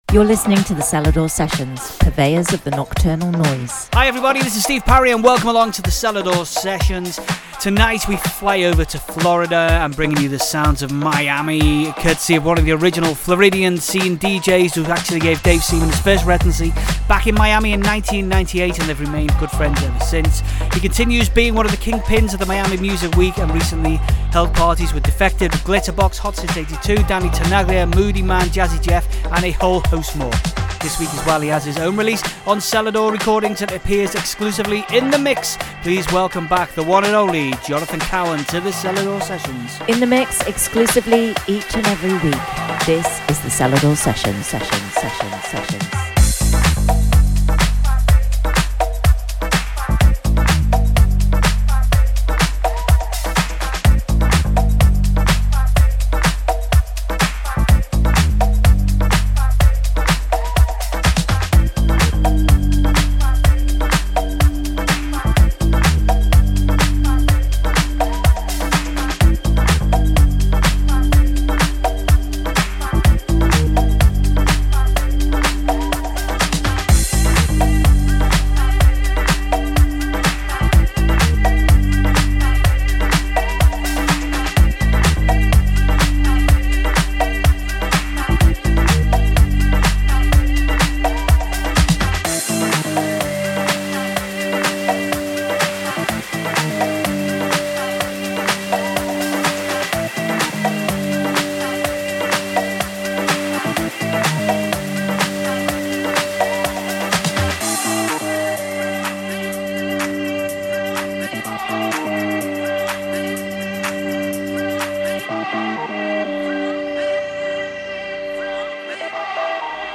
Also find other EDM Livesets, DJ Mixes and Radio Show
bringing the house party vibes